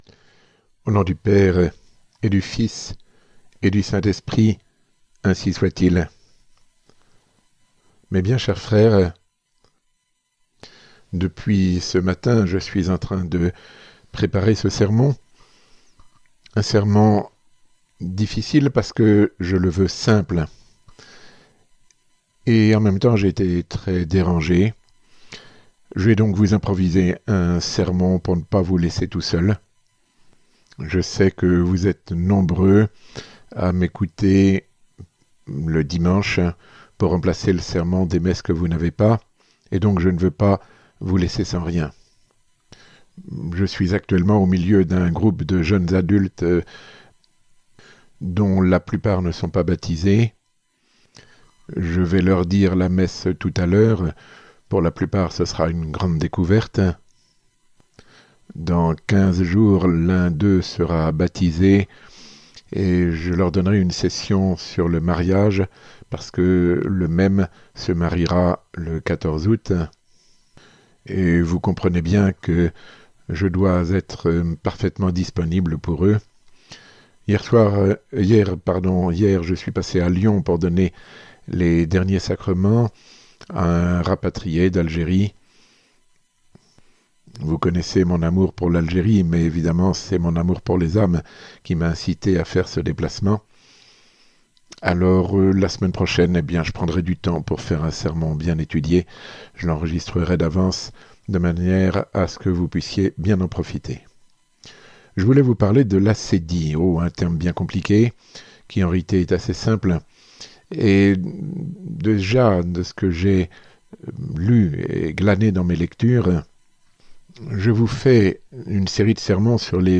Sermon ~ Mise en garde contre les vices de notre époque 13 Le remède principal